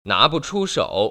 [ná‧bu chū shŏu] 나부추서우